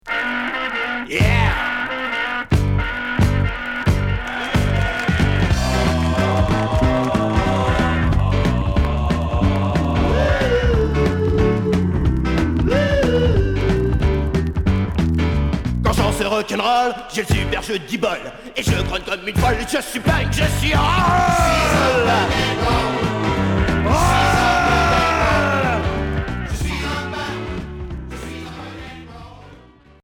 Rock variété